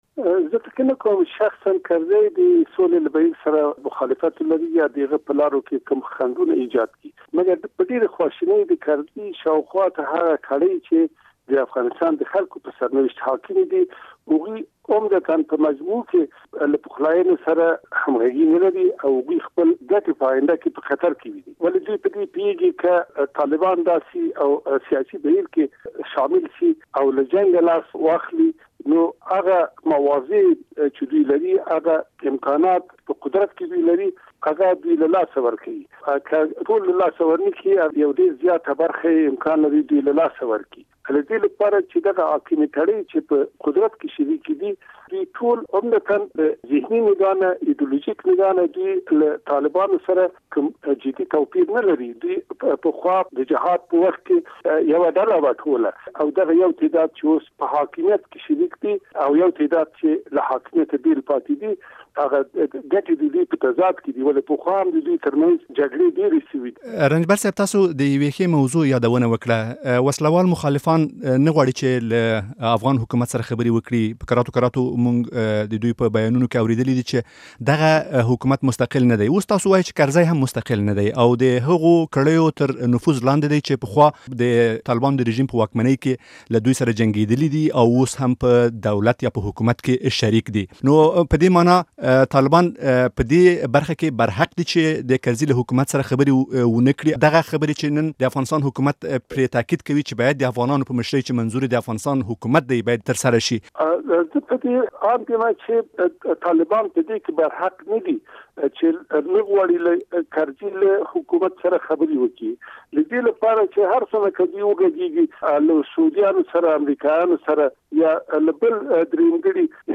د سولې د بهیر په اړه له کبیر رنجبر سره مرکه